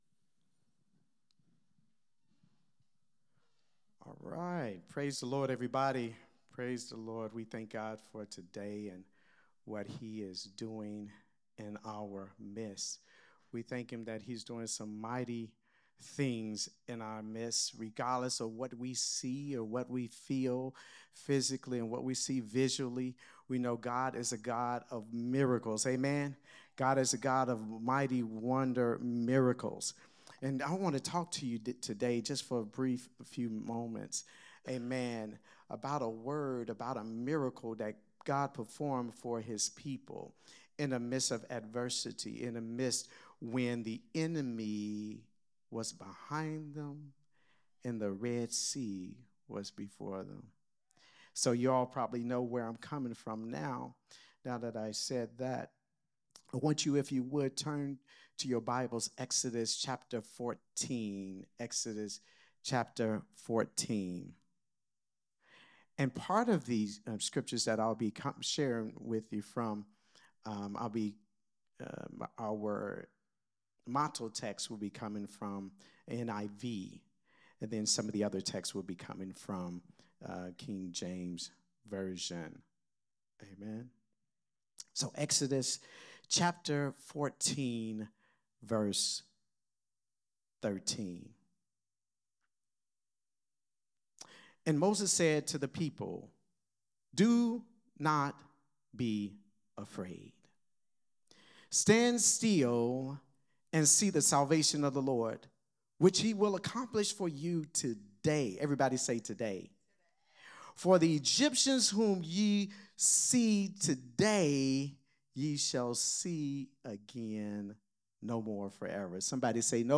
a Sunday Morning Risen Life teaching